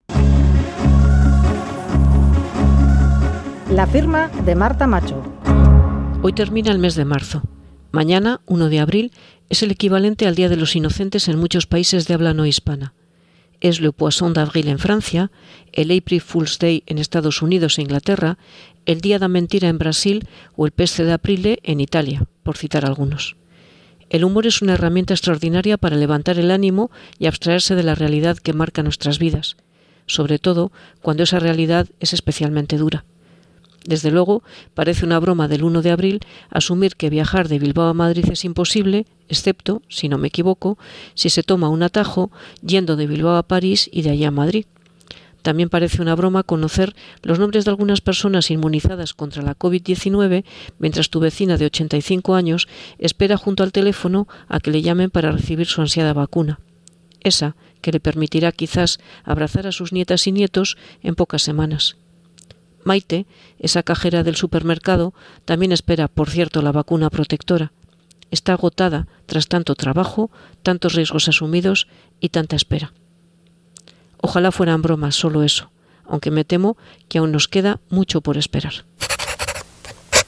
Radio Euskadi LA FIRMA Bilbao - Madrid vía París Publicado: 31/03/2021 08:22 (UTC+2) Última actualización: 31/03/2021 08:22 (UTC+2) Columna radiofónica de opinión en Boulevard de Radio Euskadi Whatsapp Whatsapp twitt telegram Enviar Copiar enlace nahieran